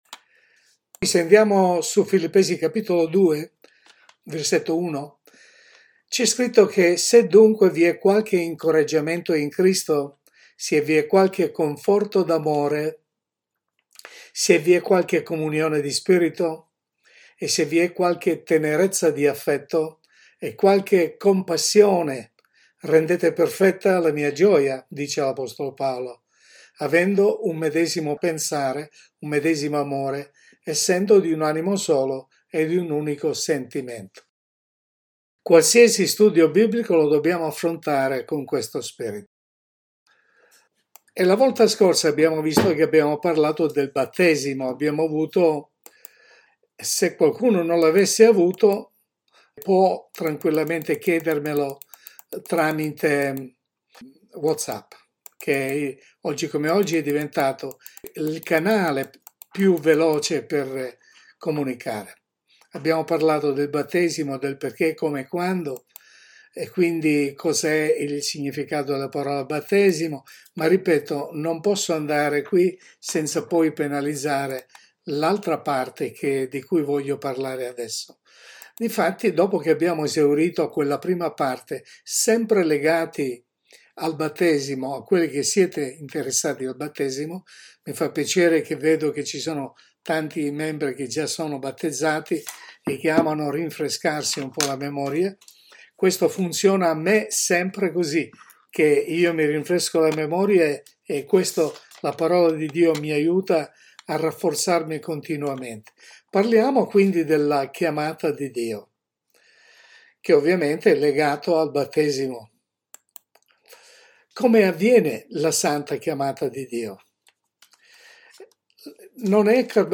Il Vero Battesimo-Parte 4-La Chiamata di Dio (Studio Biblico